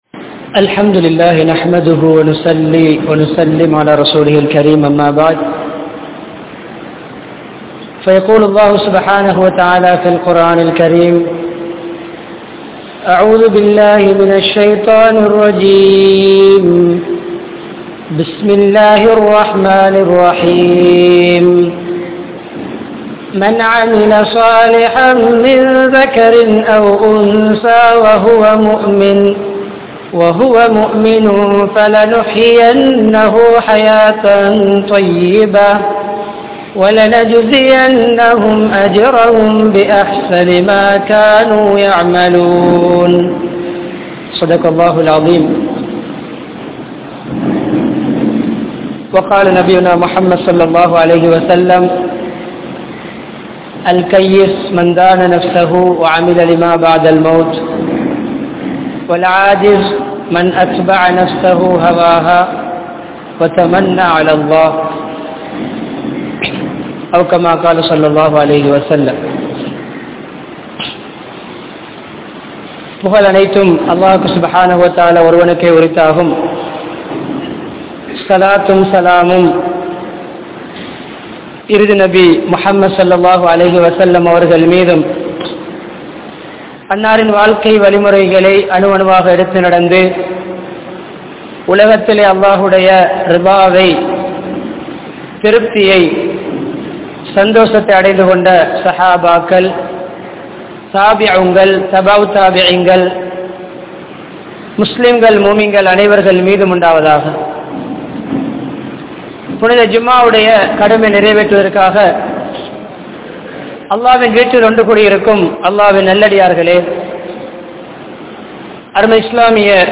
Manamaana Vaalkai Veanduma? (மனமான வாழ்க்கை வேண்டுமா?) | Audio Bayans | All Ceylon Muslim Youth Community | Addalaichenai
Mannar, Uppukkulam, Al Azhar Jumua Masjidh